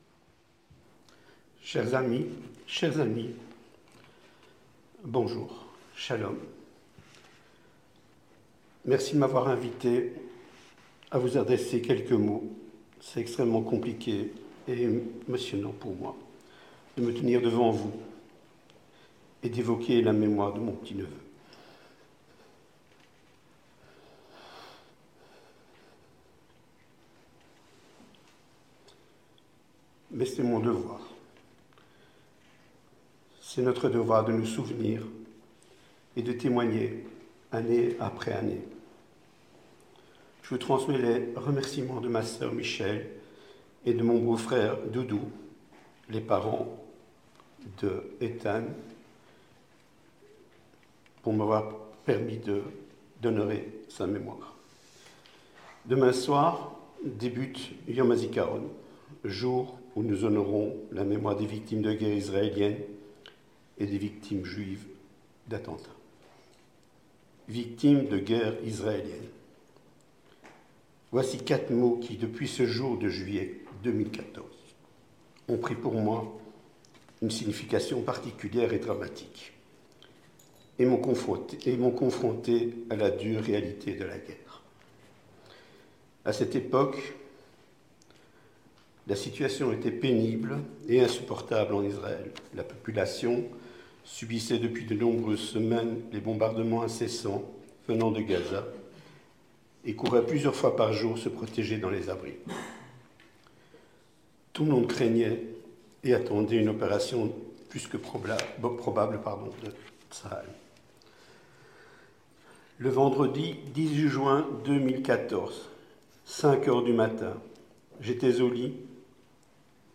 Entretien du 18h - Reportage en direct de la cérémonie pour Yom Hazikaron organisée par l'Ambassade d'Israël en Belgique